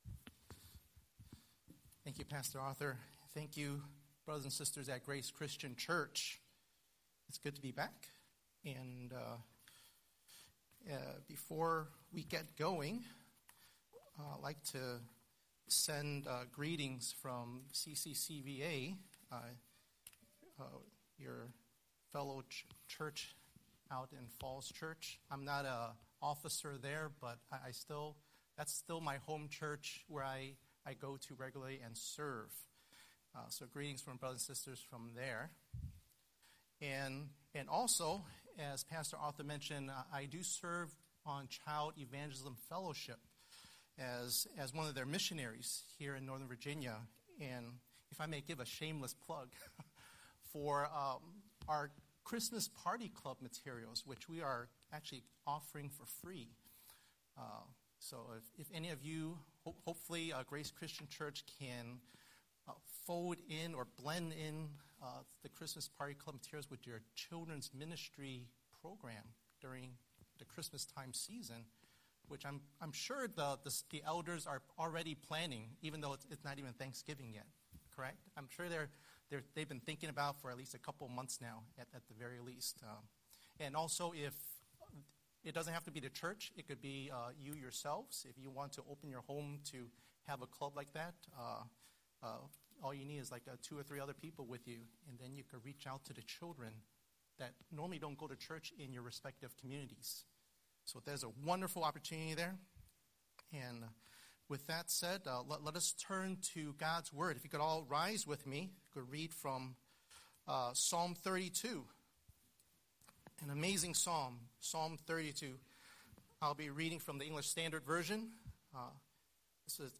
Scripture: Psalm 32:1–11 Series: Sunday Sermon